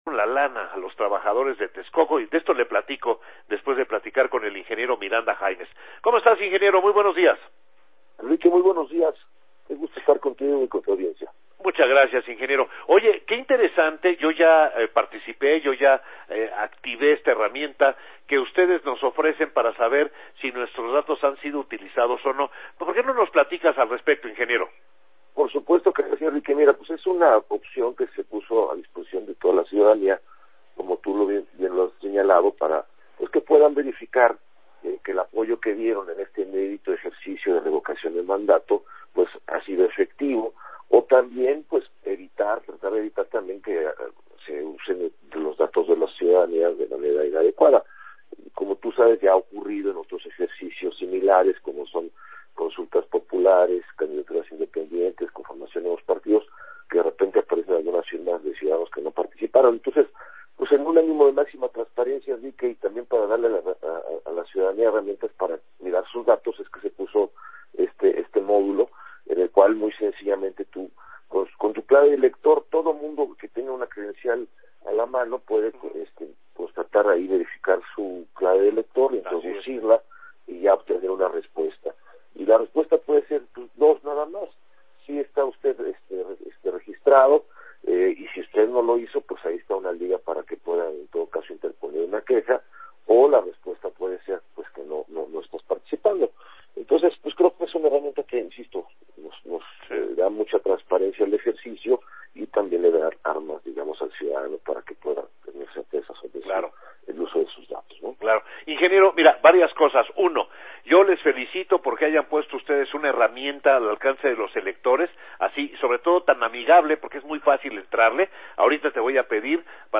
Entrevista-Rene-Miranda-1